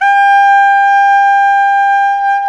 Index of /90_sSampleCDs/Roland LCDP07 Super Sax/SAX_Tenor V-sw/SAX_Tenor _ 2way
SAX TENORB0V.wav